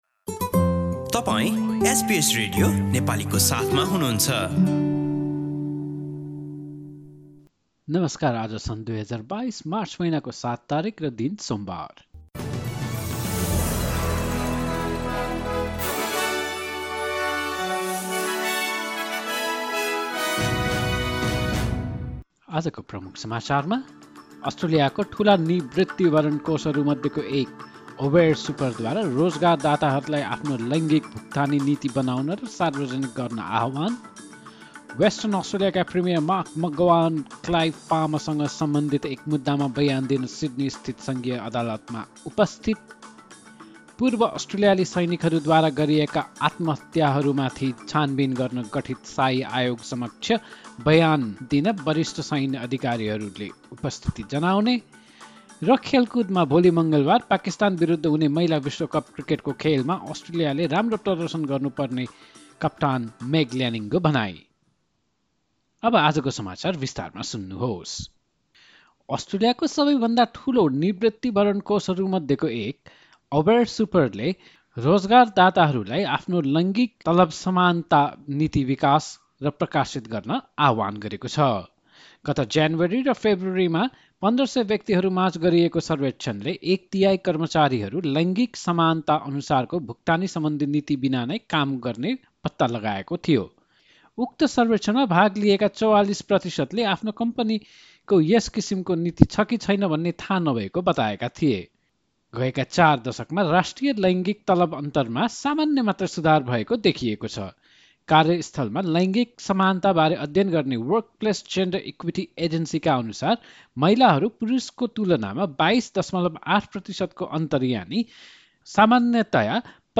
एसबीएस नेपाली अस्ट्रेलिया समाचार: सोमवार ७ मार्च २०२२